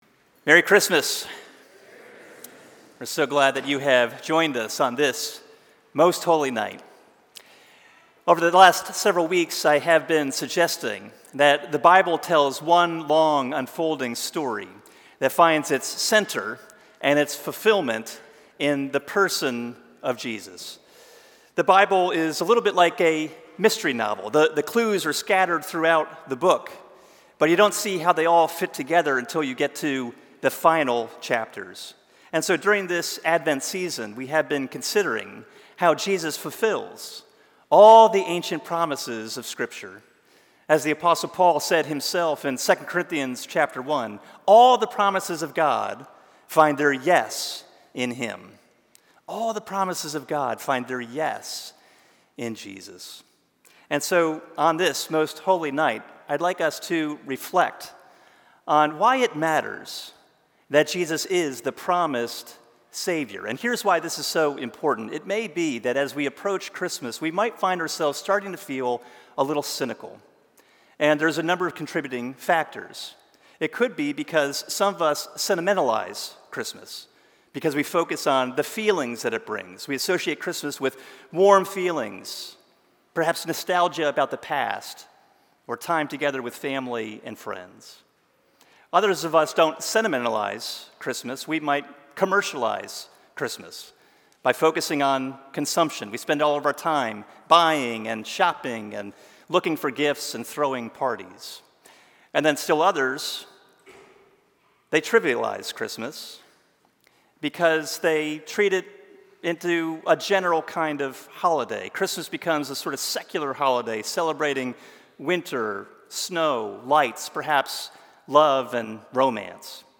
Christmas Eve sermon